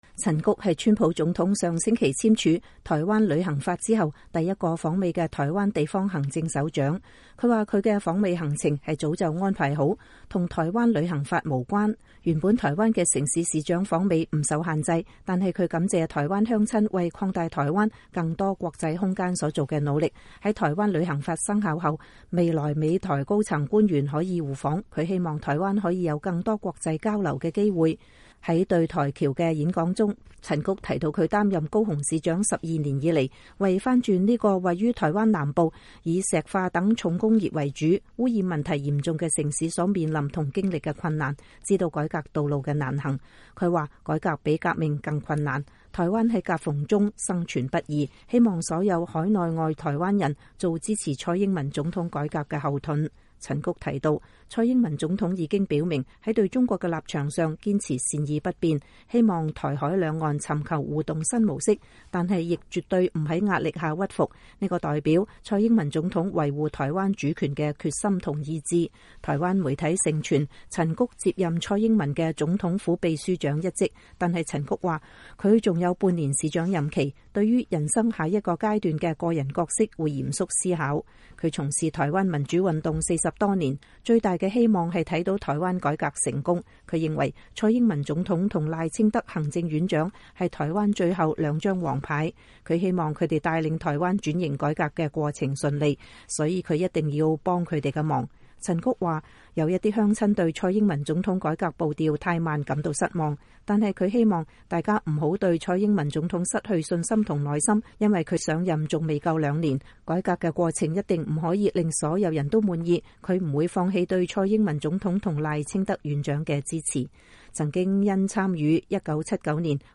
正在美國訪問的台灣高雄市長陳菊星期一在馬里蘭州一個演講中呼籲海外台僑做蔡英文總統改革的後盾，支持她不在壓力下屈服和對台灣的承諾。